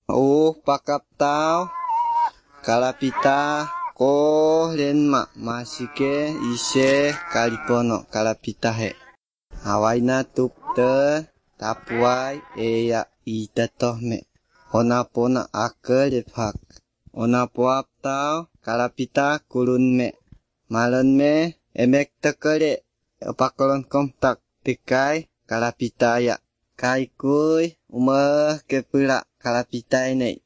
It’s the sound of a monkey crying in the background at the beginning of the recording and the multiple occurence of “pita” as in pitahaya fruit that give the impression of an Amazonian language to me.